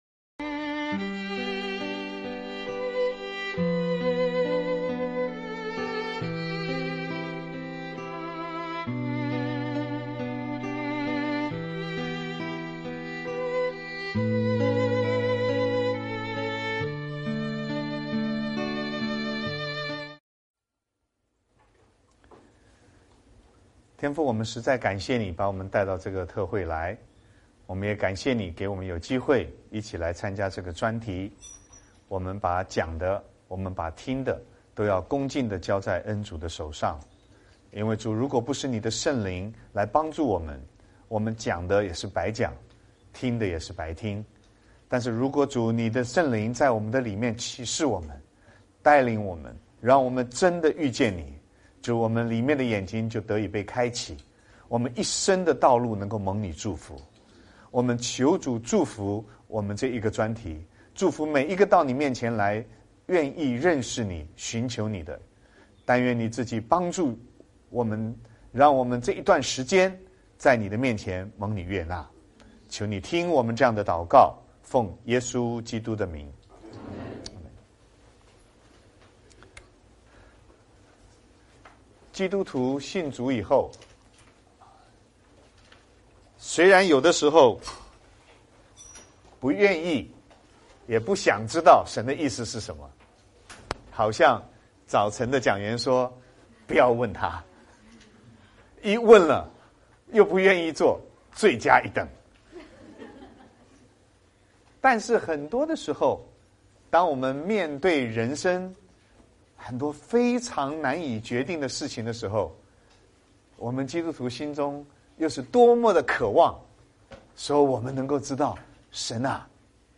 16街讲道录音 - 如何明白神的引导01